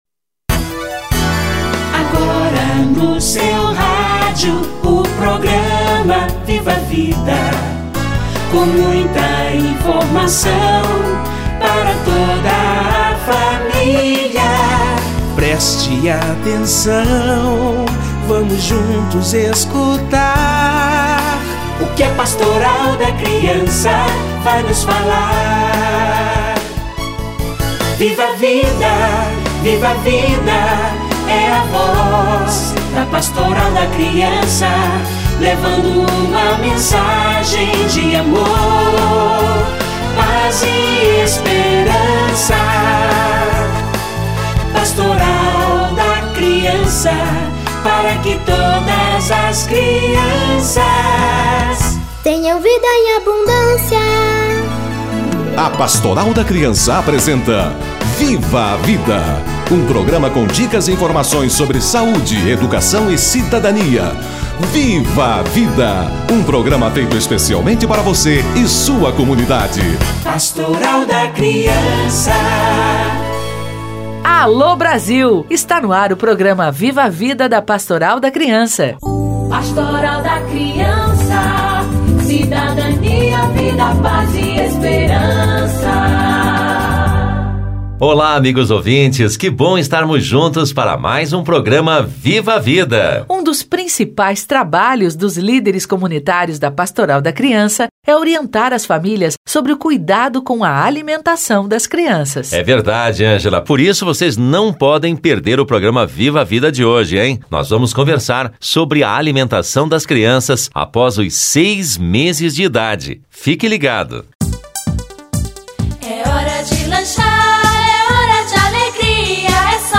Introdução de novos alimentos - Entrevista